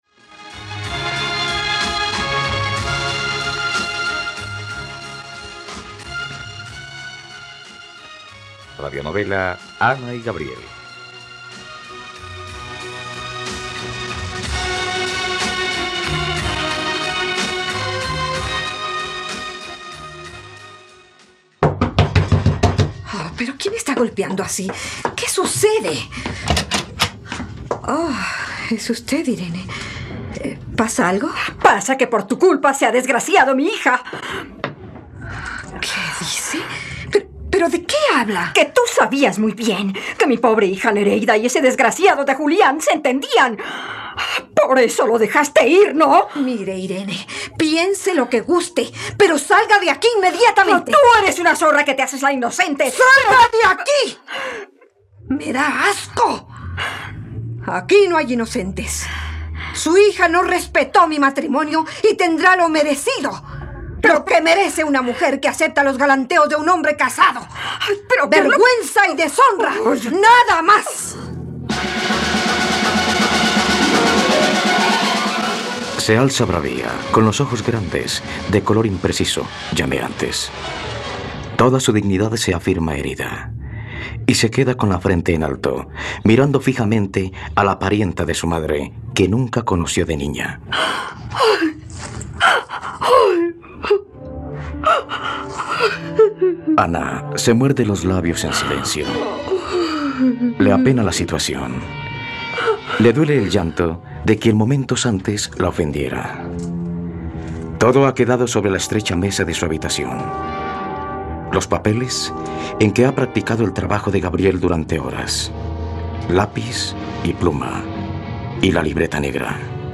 Ana y Gabriel - Radionovela, capítulo 17 | RTVCPlay